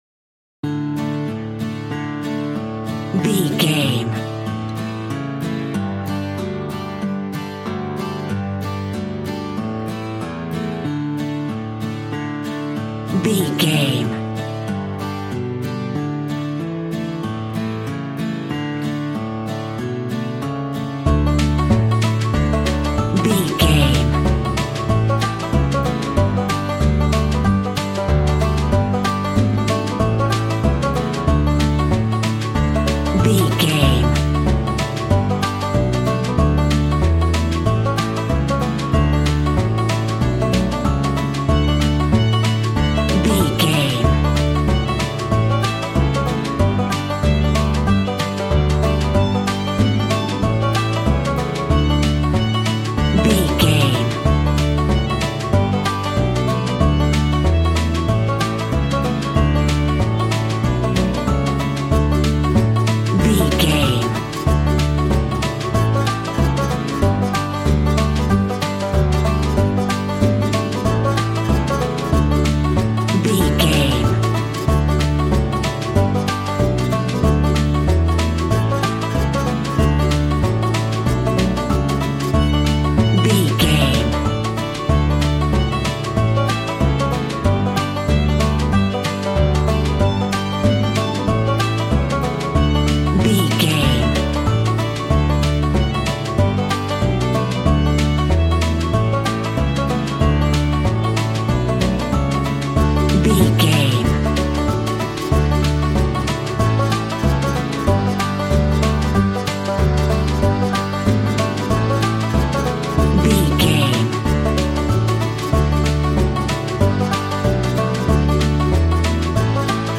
Ionian/Major
D
Fast
fun
bouncy
positive
double bass
drums
acoustic guitar